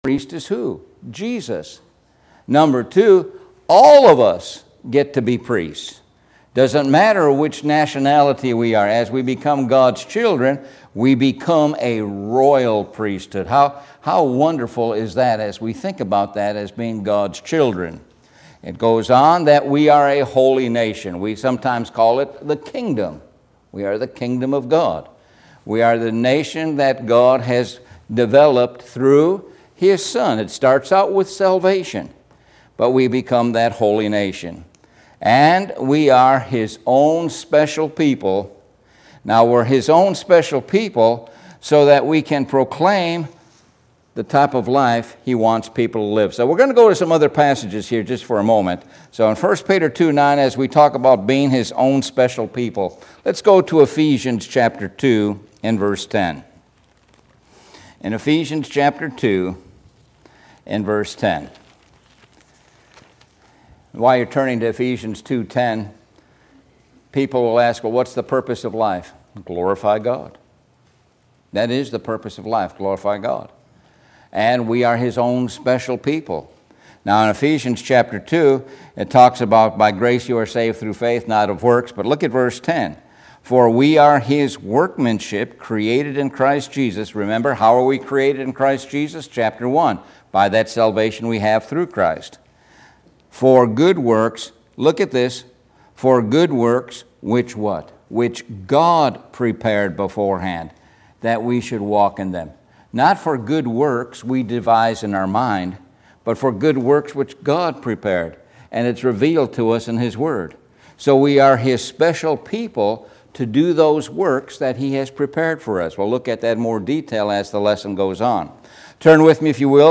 Sun PM Sermon